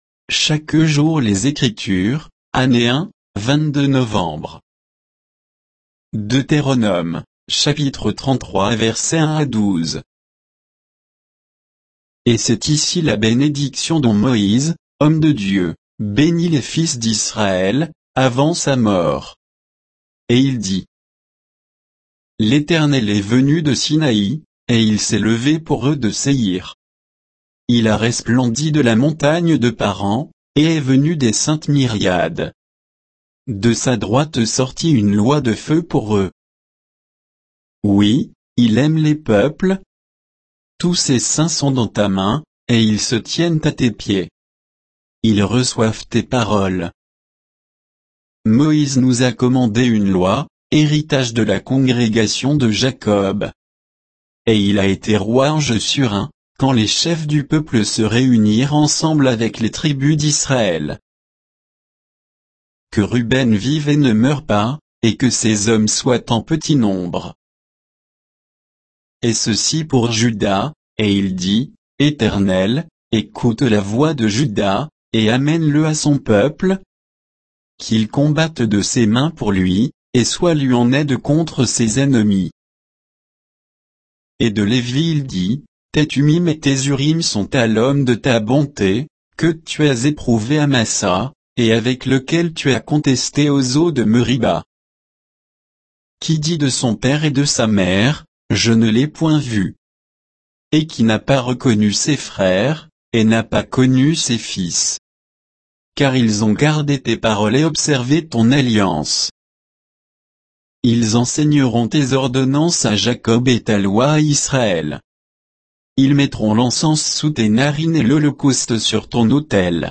Méditation quoditienne de Chaque jour les Écritures sur Deutéronome 33, 1 à 12